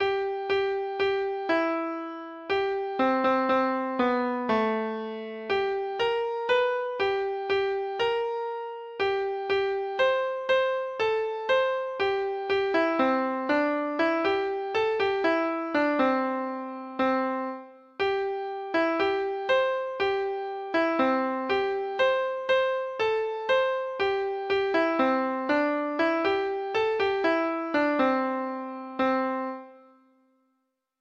Folk Songs from 'Digital Tradition' Letter T The Trooper and the Maid
Free Sheet music for Treble Clef Instrument
Traditional Music of unknown author.